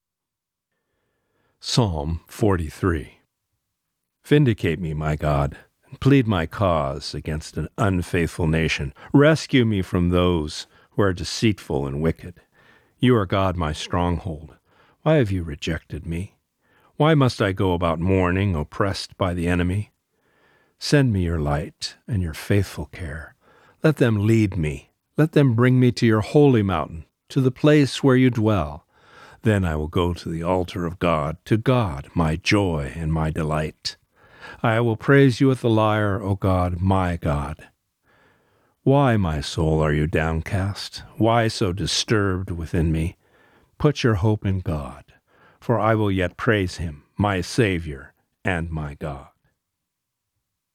Reading: Psalm 43